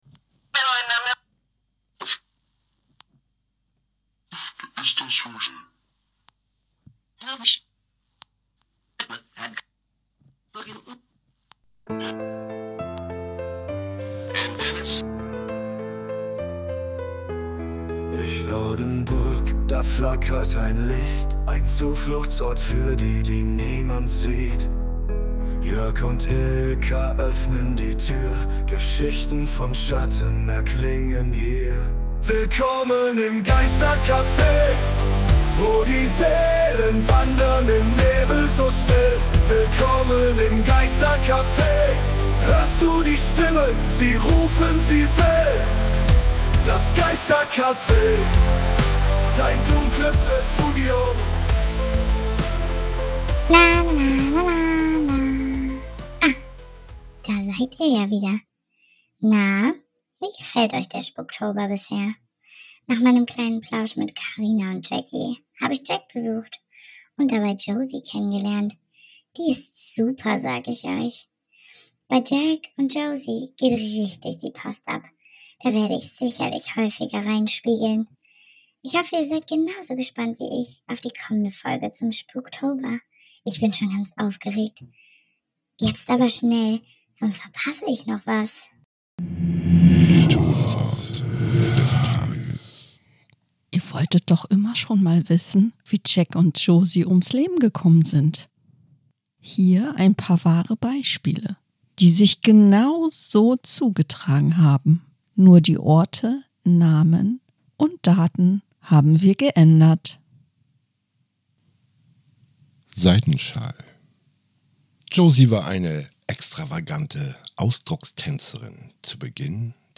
In der elften Episode des Spuktober 2025 werfen wir abermals einen Blick auf das, was euch in den kommenden Nächten erwartet, und starten mit einer Geschichte, die euch garantiert das Blut in den Adern gefrieren lässt. Dazu gesellen sich einige unserer Freunde, die den Spuktober mit ihren Stimmen noch lebendiger und gruseliger machen.